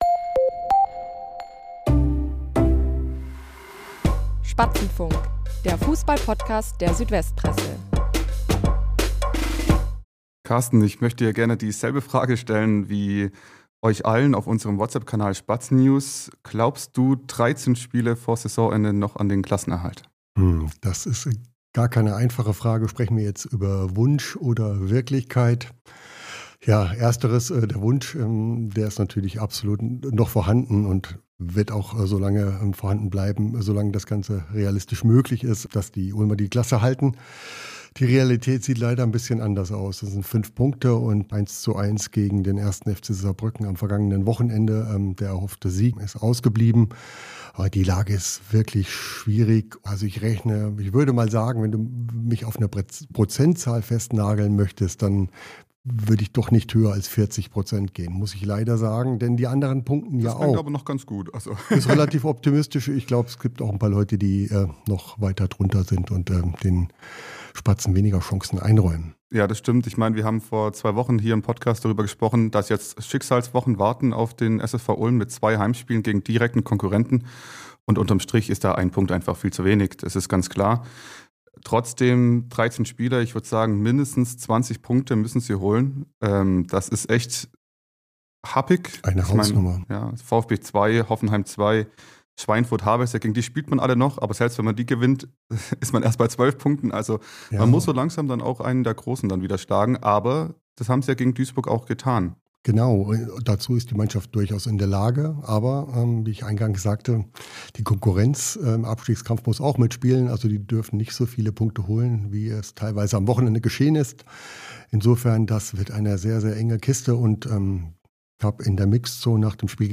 Klar ist: Die Spatzen stecken nach dem 1:1 gegen den 1. FC Saarbrücken weiterhin tief im Tabellenkeller fest. Glaubt die Redaktion der SÜDWEST PRESSE 13 Spiele vor Saisonende noch an die Chance auf den Drittliga-Verbleib? Das beantworten die Sportreporter